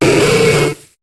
Cri de Saquedeneu dans Pokémon HOME.